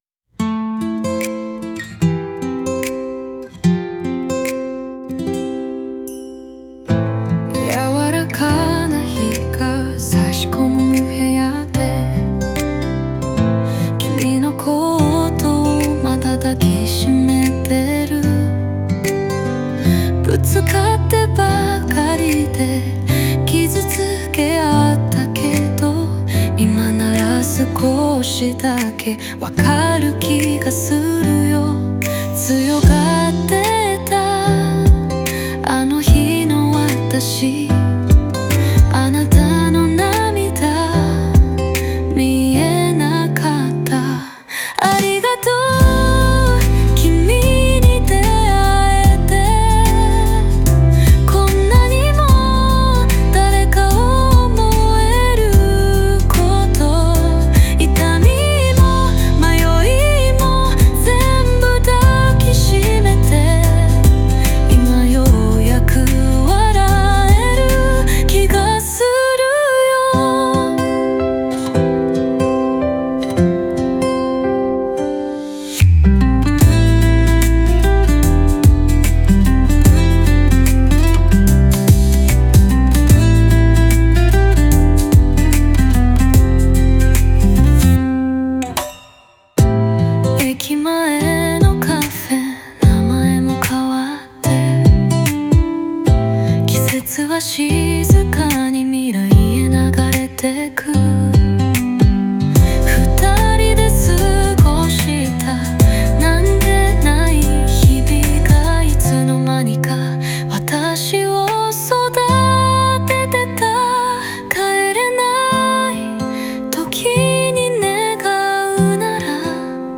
後悔ではなく、愛し方を学んだこと、日々の記憶の大切さを静かに受け止める姿が、やわらかなメロディとともに描かれています。